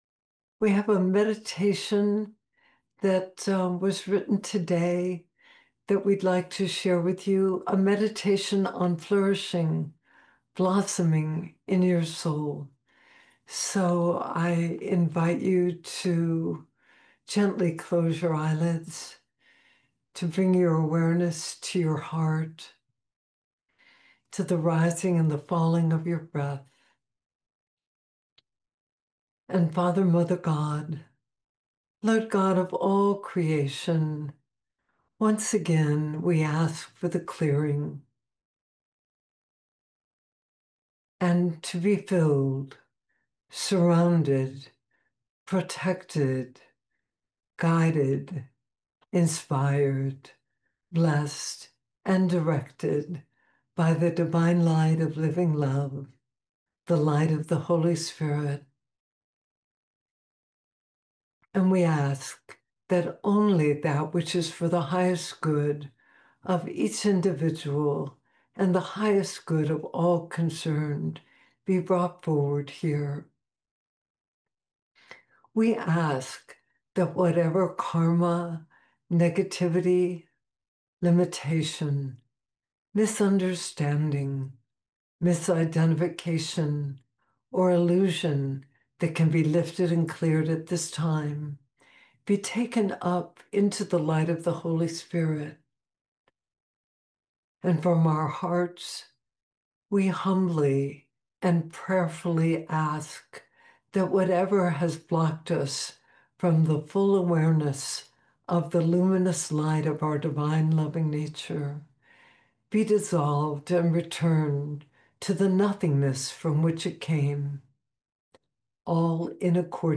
FLOURISHING IN THE SEASON OF LIGHT & LOVE MEDITATION